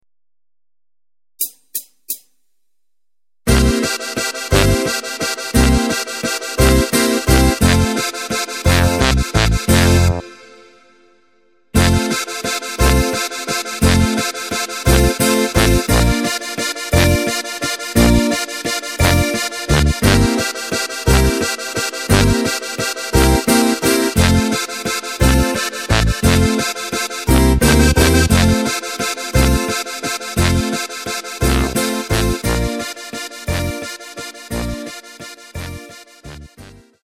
Takt:          3/4
Tempo:         174.00
Tonart:            F
Walzer für Steirische Harmonika!
Playback Demo